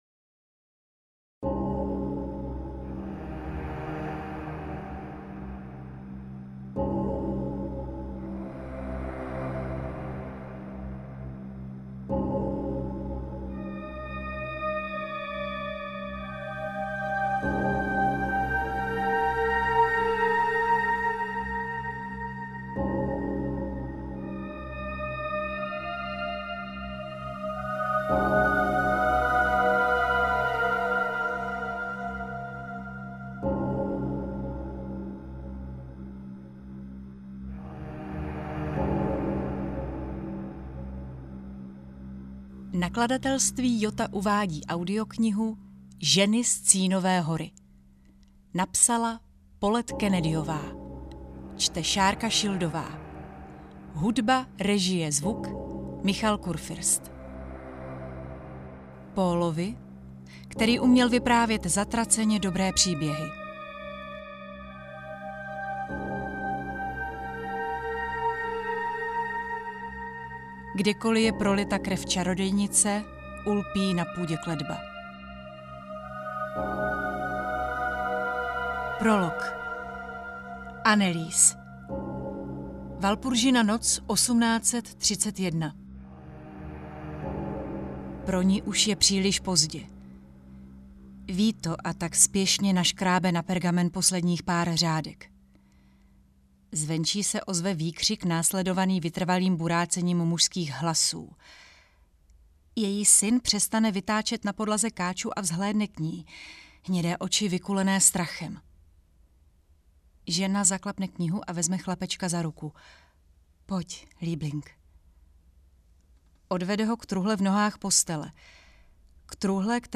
Ženy z Cínové hory audiokniha
Ukázka z knihy